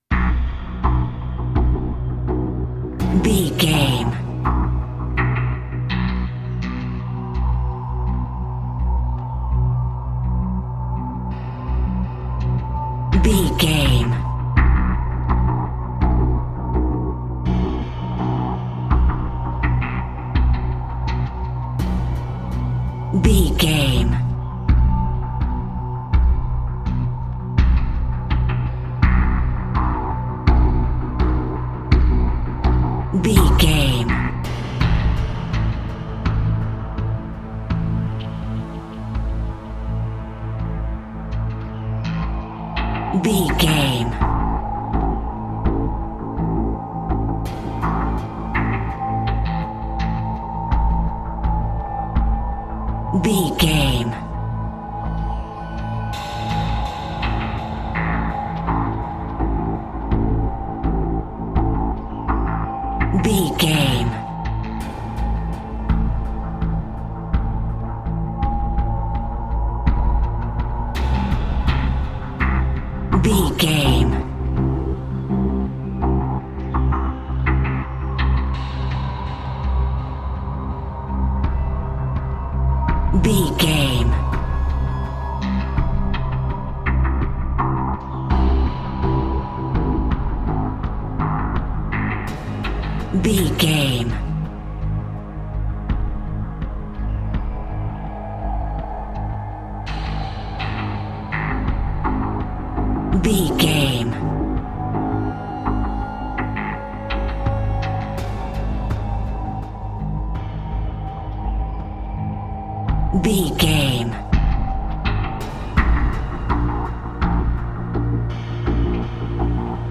Thriller
Aeolian/Minor
synthesiser
drum machine
ominous
dark
suspense
haunting
creepy
spooky